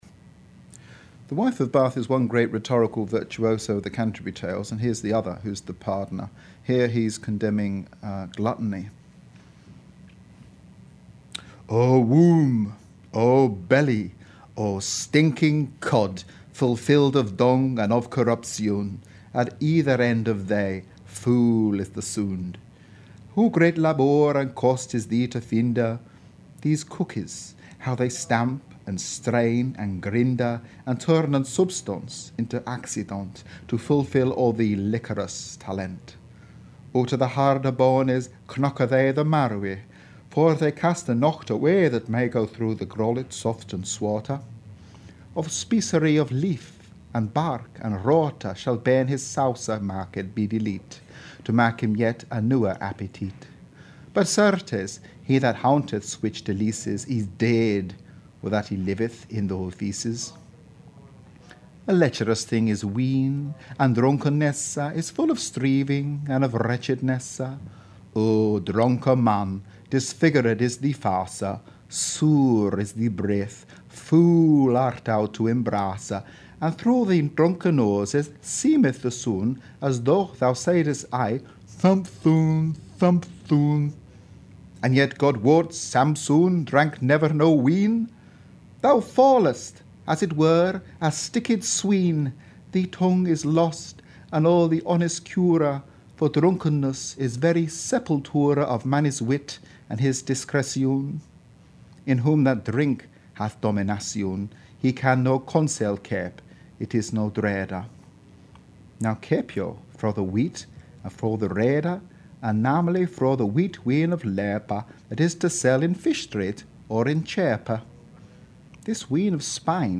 Reads Chaucer